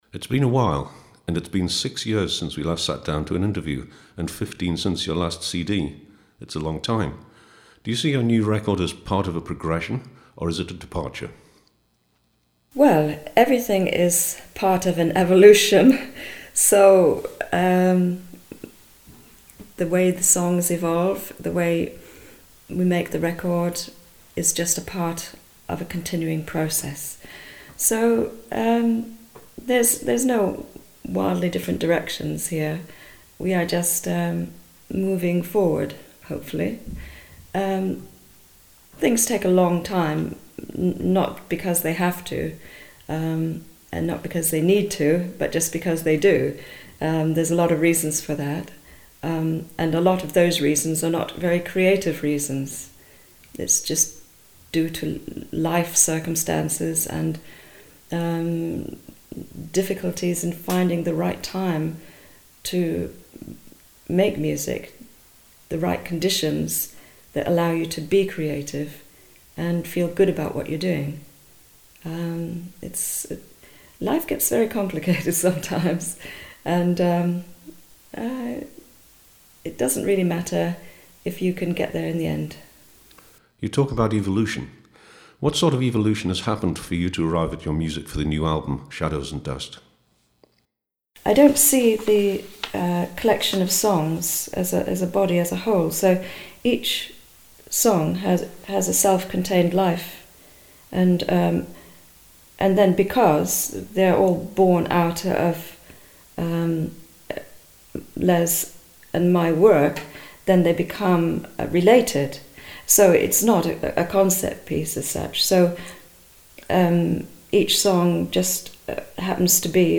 Lene Lovich in interview